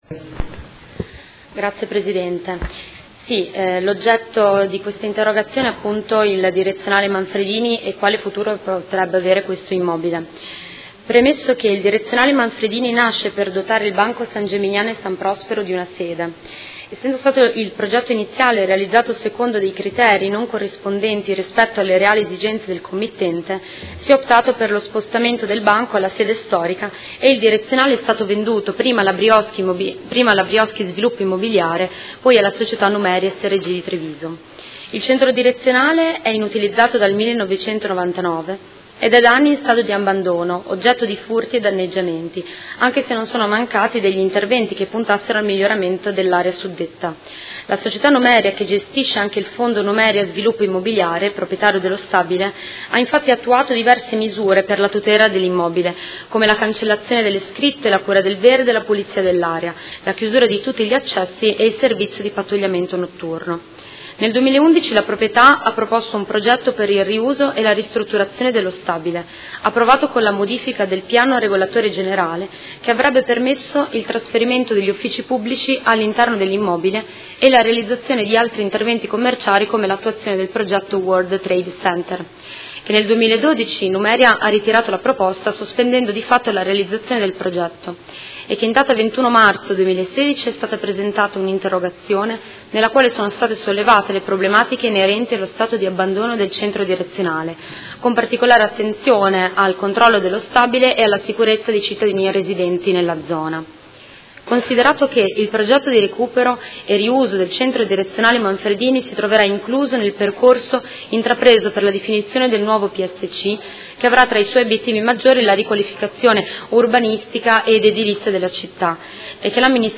Seduta del 9/11/2017. Interrogazione delle Consigliere Venturelli e Pacchioni (PD) avente per oggetto: Quale futuro per il Direzionale Manfredini?